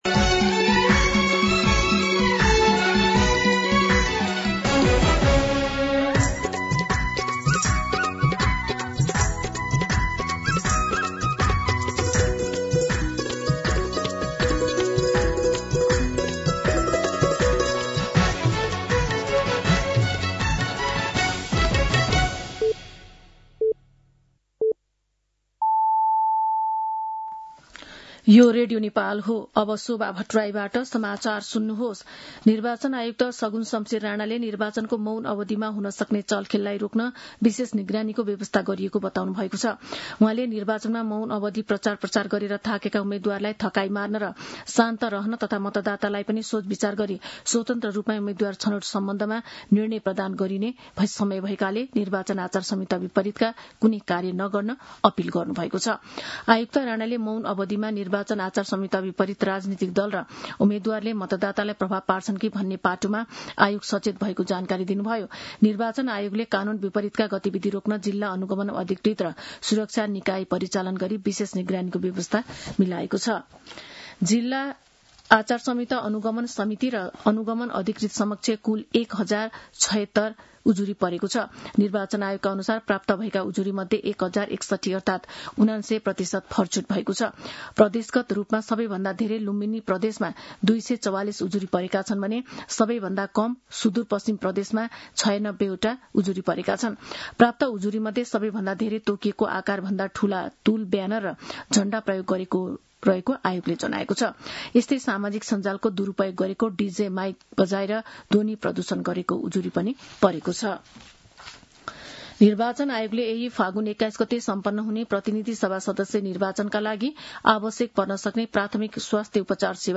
मध्यान्ह १२ बजेको नेपाली समाचार : १६ फागुन , २०८२